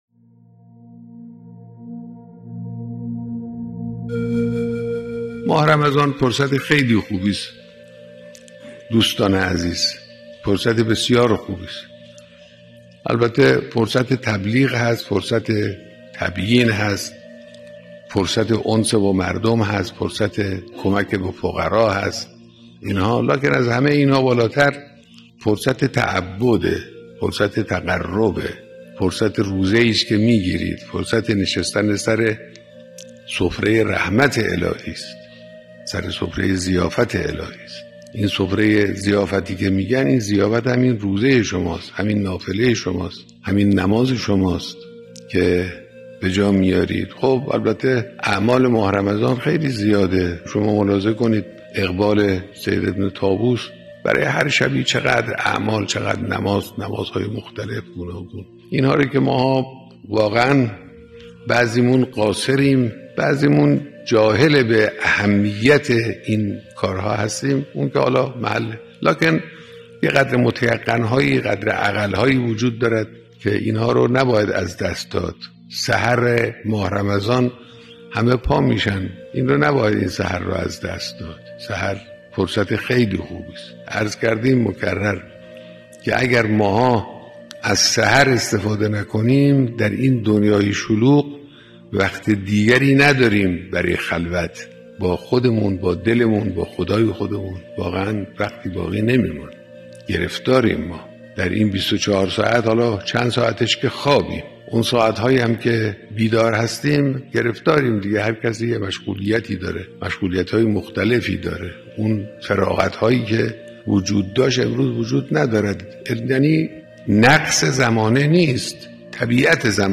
دانلود صوت سخنرانی رهبر